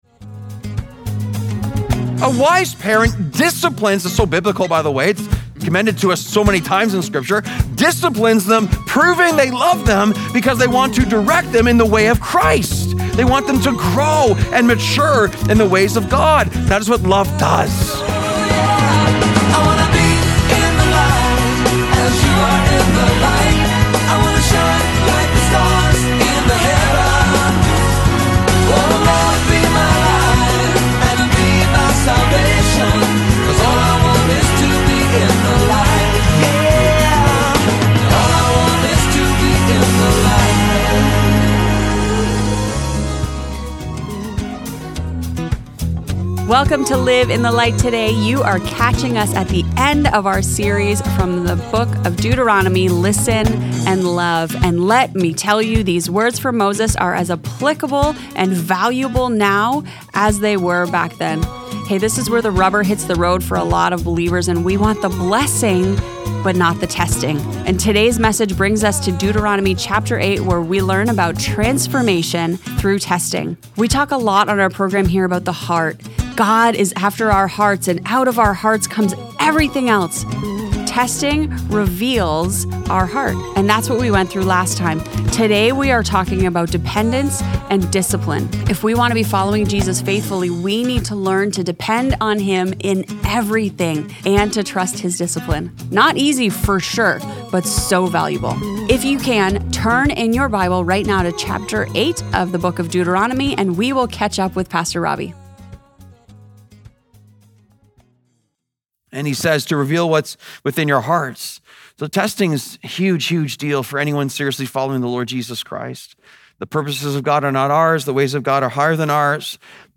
In this sermon, we uncover the truth that transformation doesn't come through comfort but through testing. God uses our difficult circumstances to result in our greatest blessings.